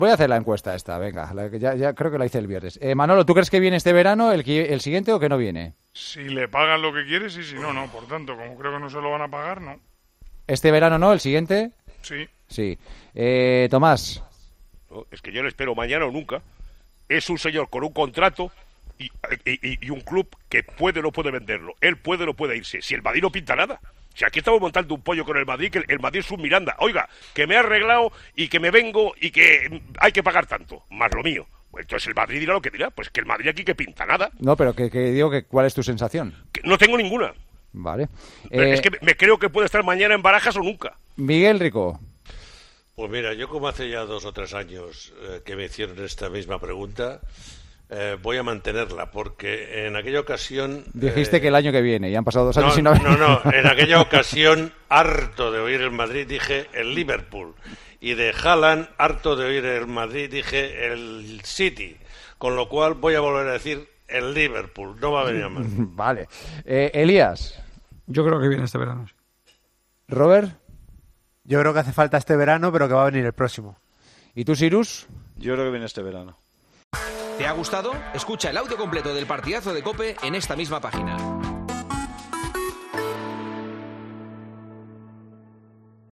Los tertulianos de El Partidazo de COPE responden a la gran pregunta sobre Mbappé